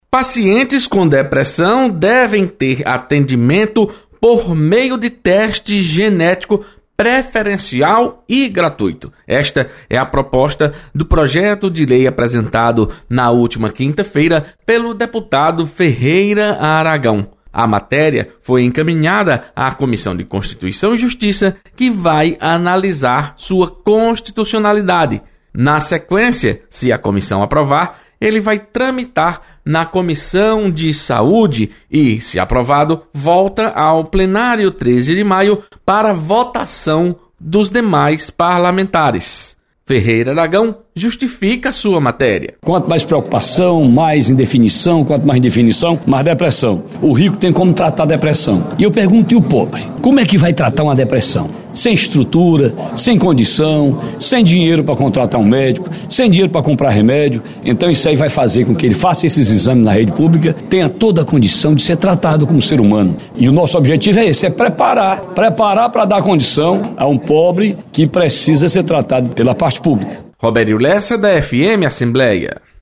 Você está aqui: Início Comunicação Rádio FM Assembleia Notícias Projeto